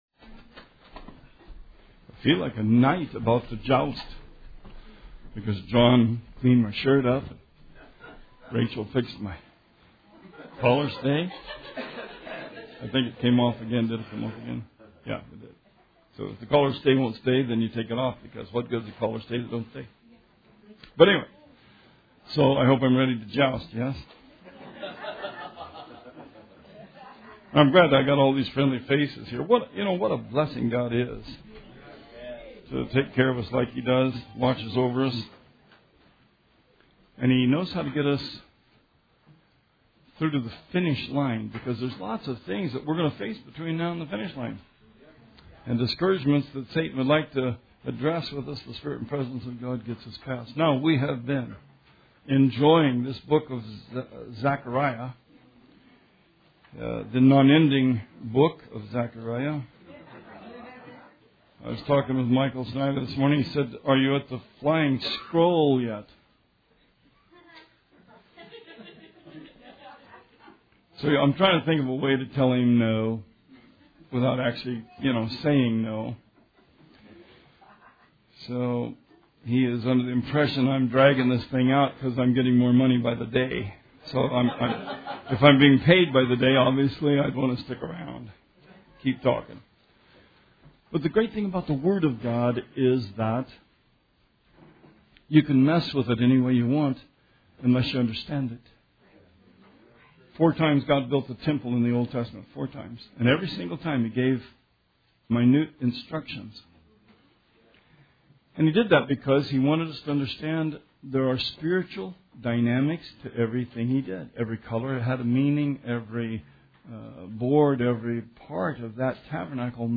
Sermon 2/18/18